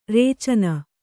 ♪ rēcana